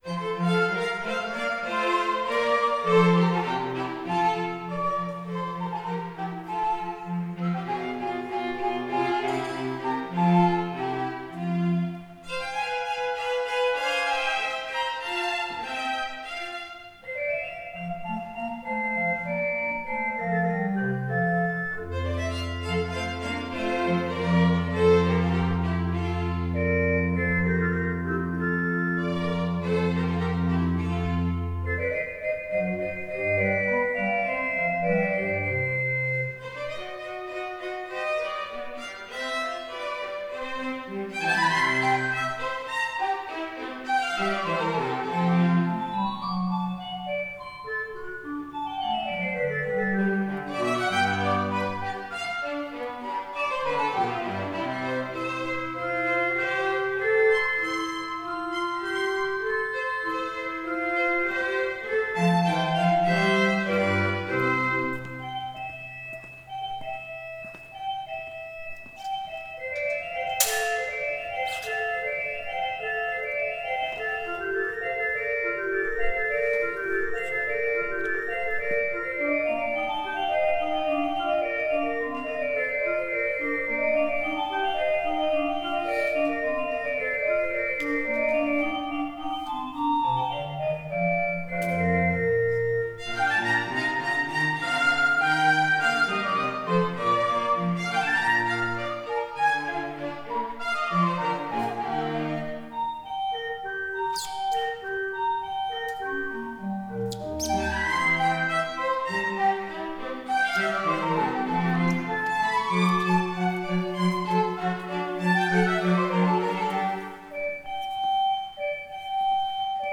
spielte das mit unserem Chor befreundete Ensemble BONnACCORD
im gestrigen Gottesdienst – eine muntere Überraschung!
Blockflöten
Orgel
Violinen
Viola
Cello